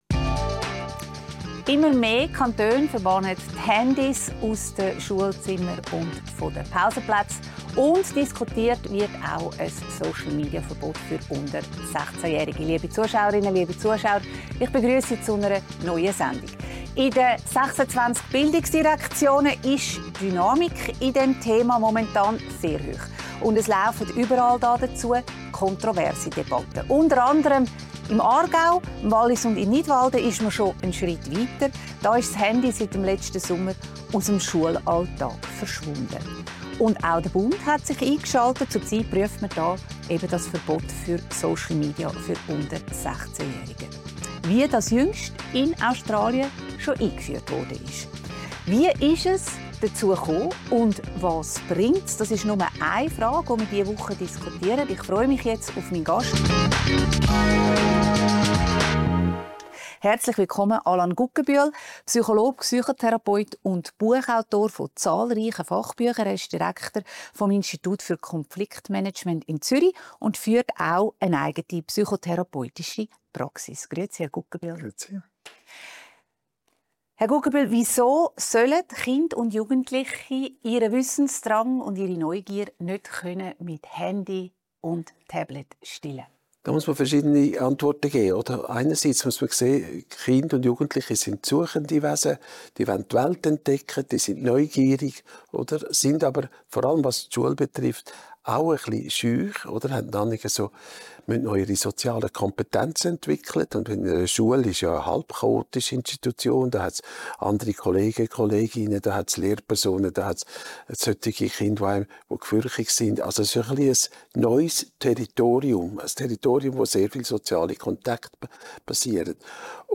Beschreibung vor 5 Monaten Immer mehr Kantone führen Handyverbote an Schulen ein. Gleichzeitig wird über ein mögliches Social-Media-Verbot für Jugendliche unter 16 Jahren diskutiert. Im Gespräch